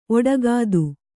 ♪ oḍagādu